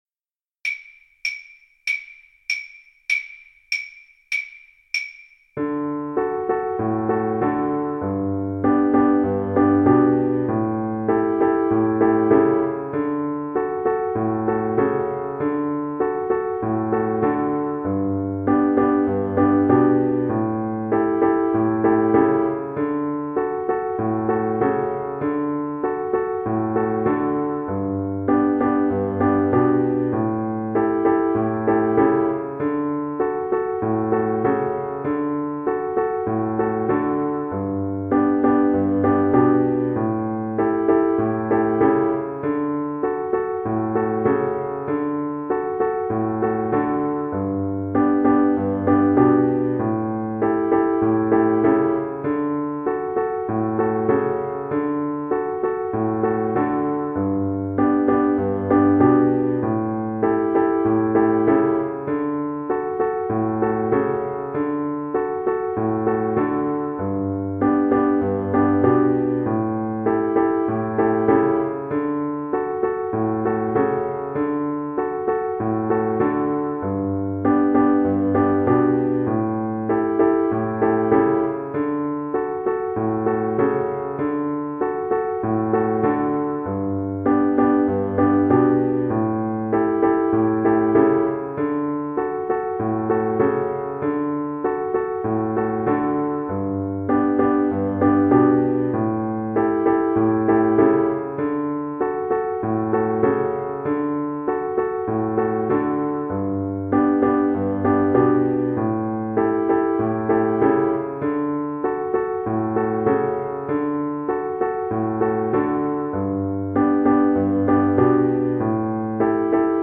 4/4 (View more 4/4 Music)
D major (Sounding Pitch) (View more D major Music for Choir )
Choir  (View more Beginners Choir Music)
World (View more World Choir Music)